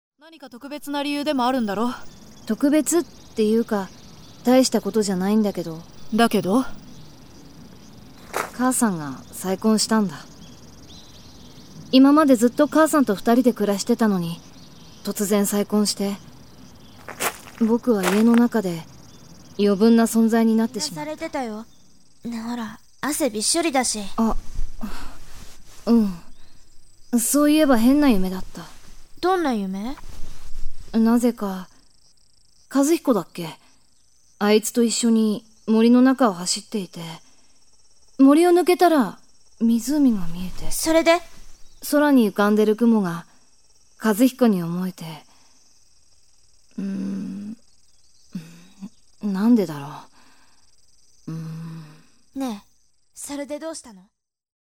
口調 挑戦的